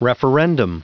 Prononciation du mot referendum en anglais (fichier audio)
Prononciation du mot : referendum